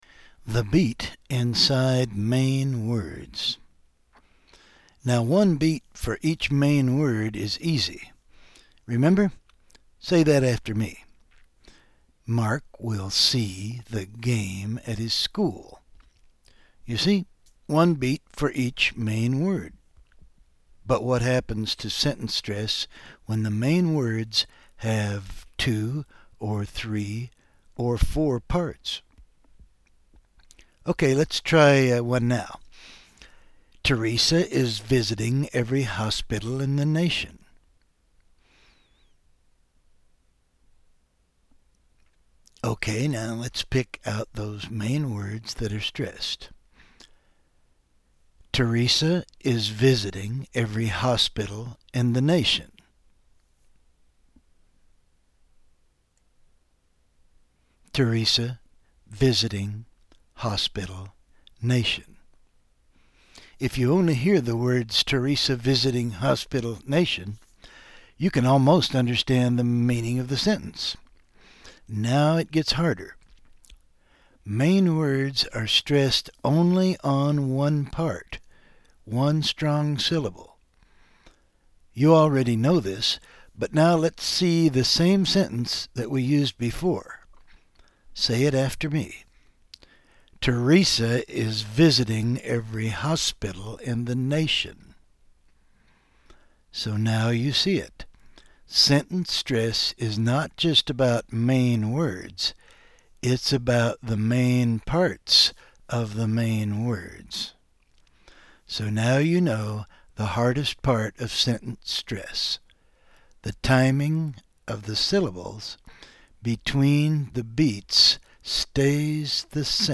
TeREsa is VISiting every HOSpital in the NAtion.